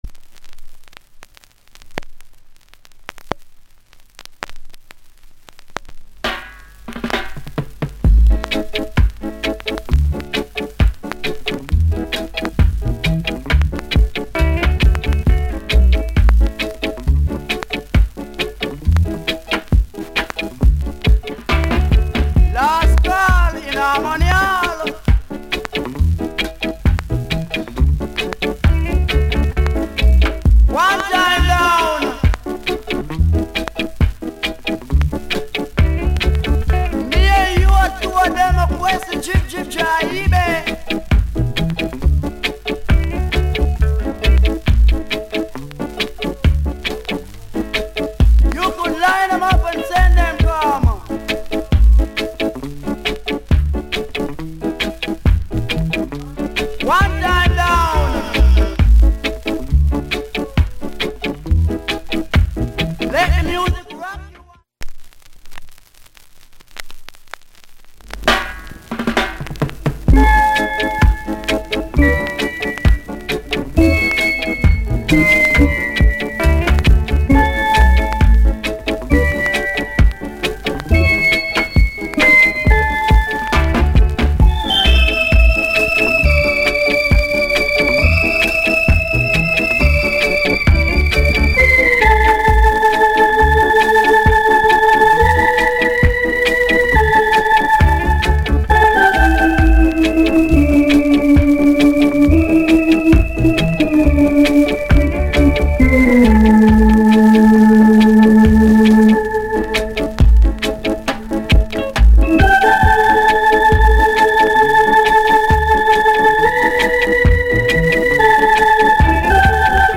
Production JA Genre Reggae70sEarly
Male DJ